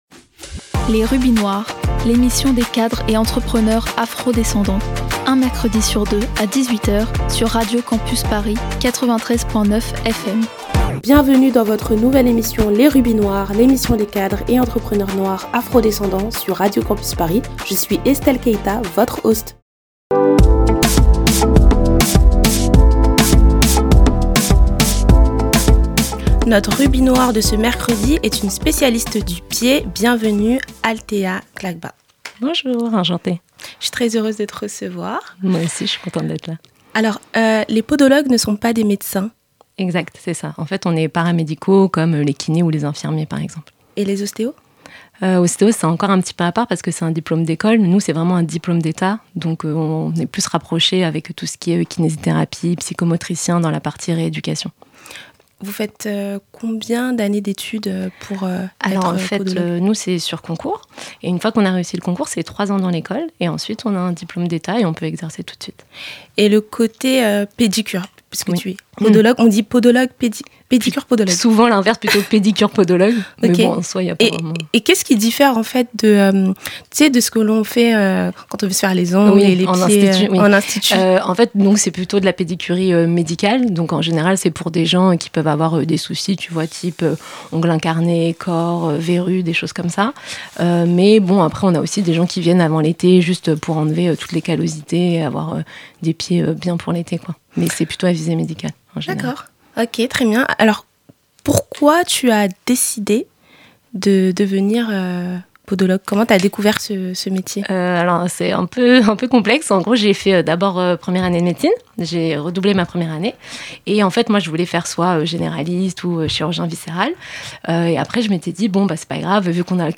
Type Entretien